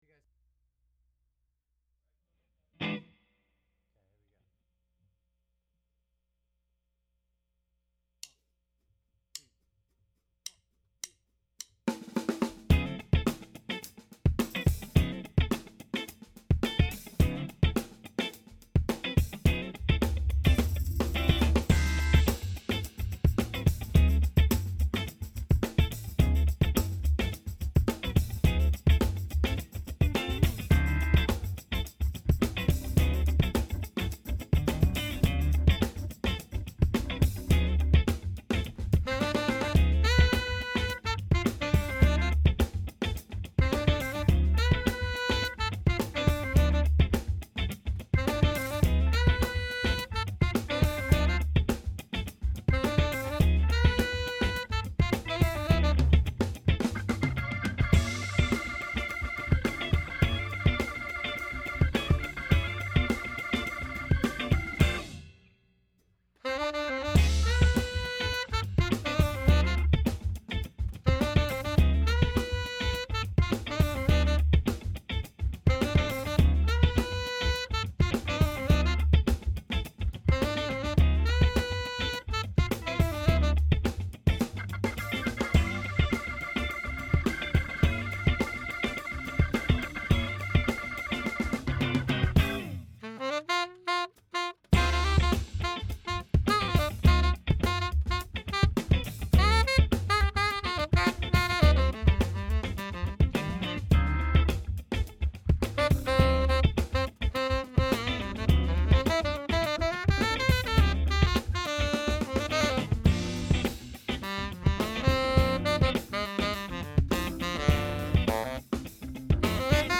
Hammond B3 Organ
sax
This is a wicked rough mix of course.